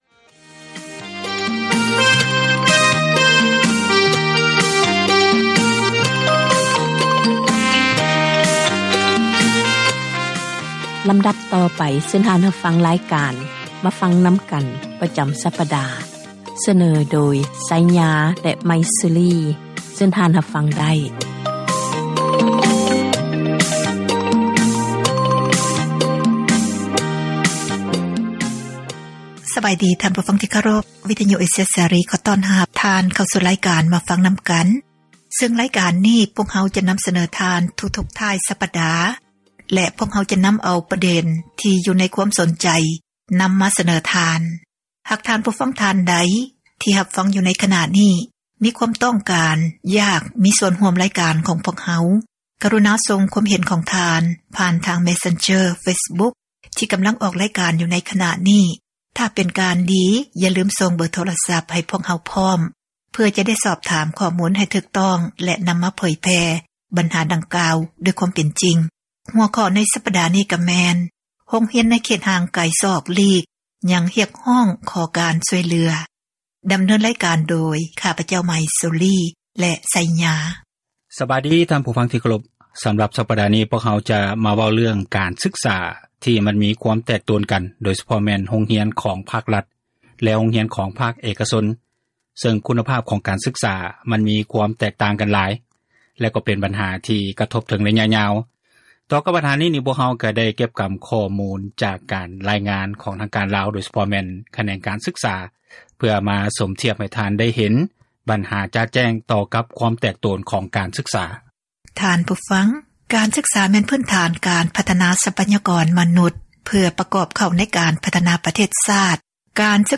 "ມາຟັງນຳກັນ" ແມ່ນຣາຍການສົນທະນາ ບັນຫາສັງຄົມ ທີ່ຕ້ອງການ ພາກສ່ວນກ່ຽວຂ້ອງ ເອົາໃຈໃສ່ແກ້ໄຂ, ອອກອາກາດ ທຸກໆວັນອາທິດ ເວລາ 6:00 ແລງ ແລະ ເຊົ້າວັນຈັນ ເວລາ 7:00